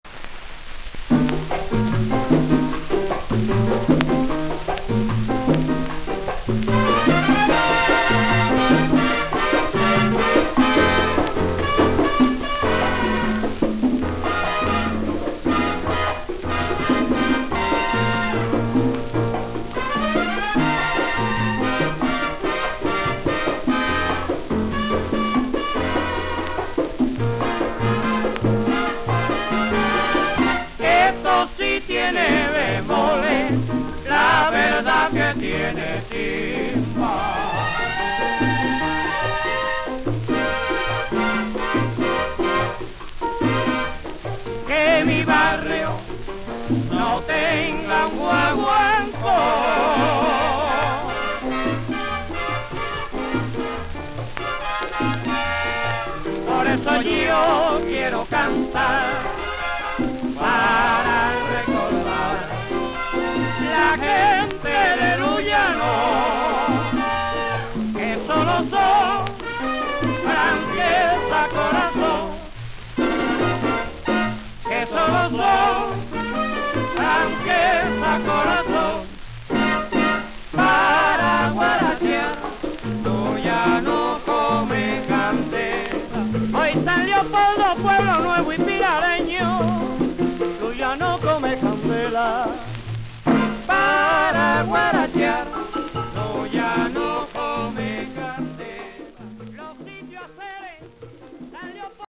GUAGUANCO
本商品は10inch SP盤78回転です。